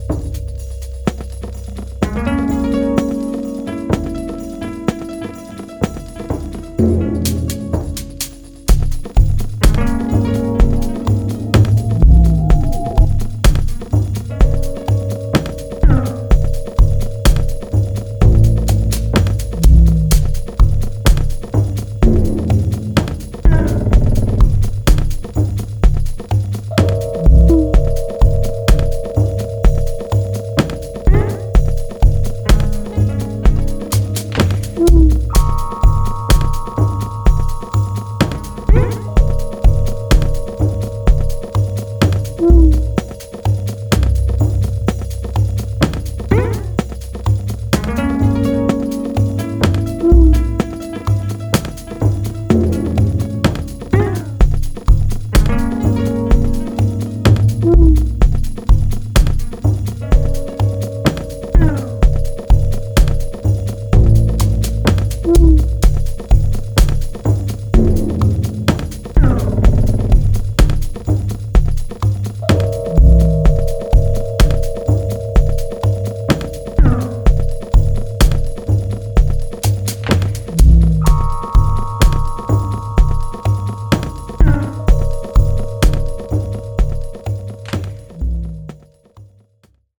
House Techno Minimal